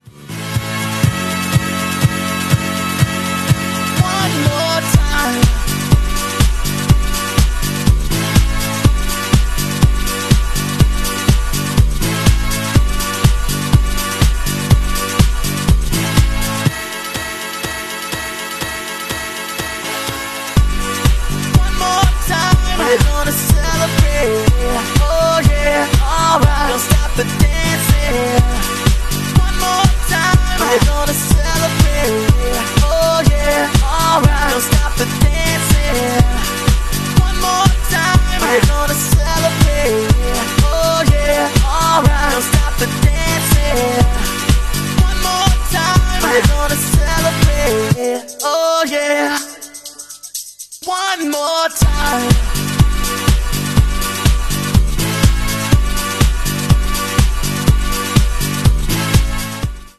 Incredible show